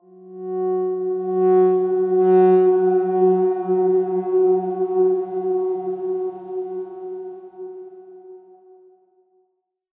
X_Darkswarm-F#3-mf.wav